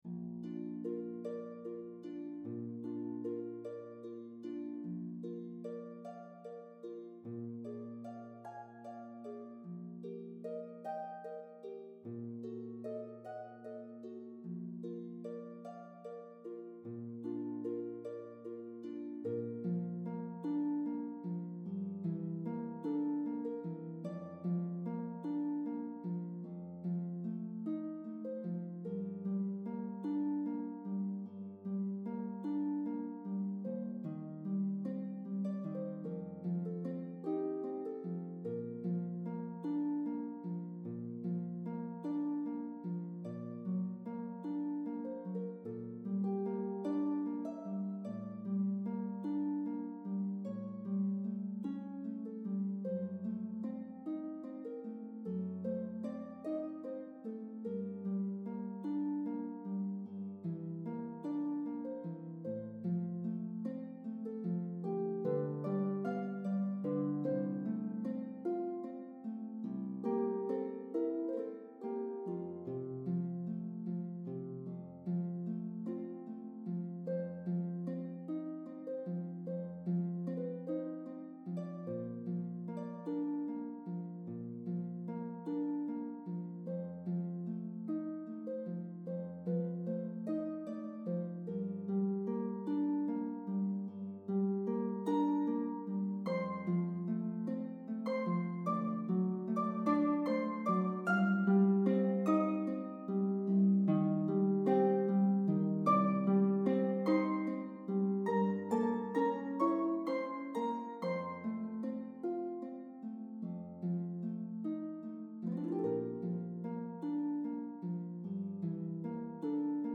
solo pedal harp